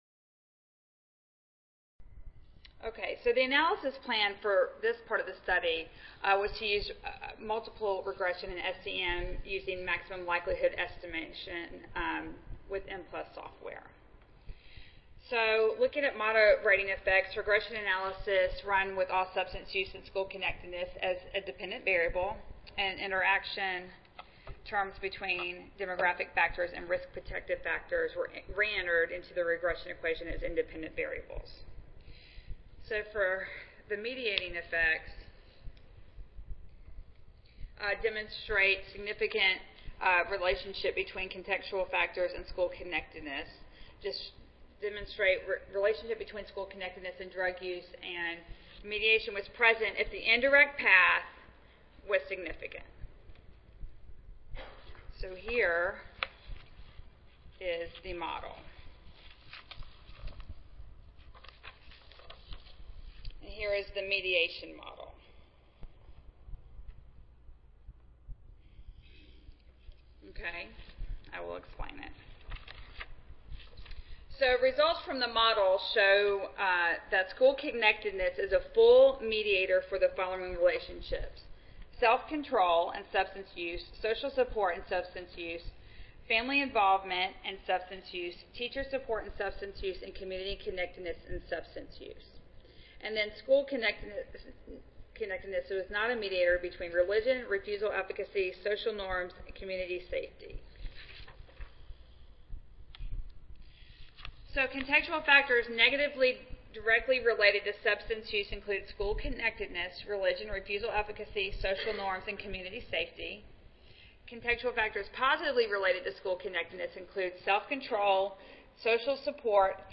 142nd APHA Annual Meeting and Exposition
Oral